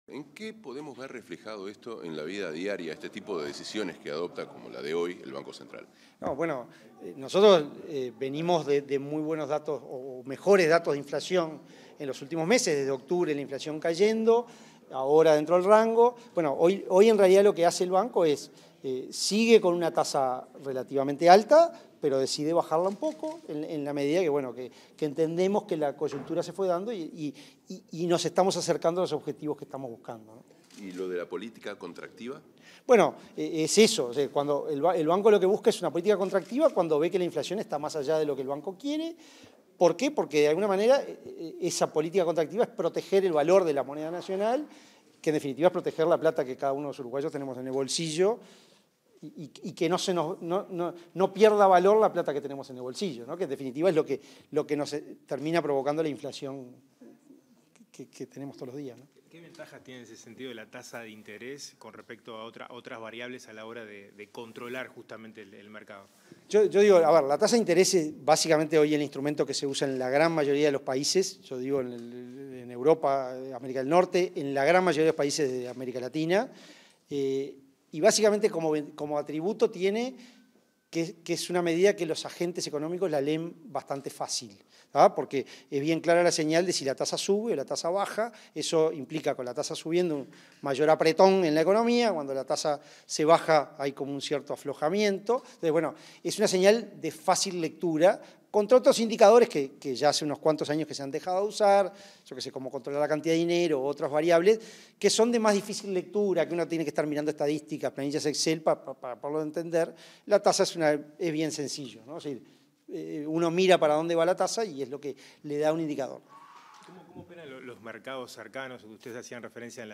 Declaraciones del presidente del Banco Central del Uruguay, Diego Labat
Declaraciones del presidente del Banco Central del Uruguay, Diego Labat 06/07/2023 Compartir Facebook X Copiar enlace WhatsApp LinkedIn Tras la conferencia de prensa con motivo de la reunión del Comité de Política Monetaria, este 6 de julio, el presidente del Banco Central del Uruguay, Diego Labat, dialogó con la prensa.